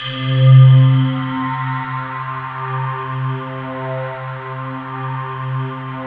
Index of /90_sSampleCDs/USB Soundscan vol.13 - Ethereal Atmosphere [AKAI] 1CD/Partition D/07-ANAPLASS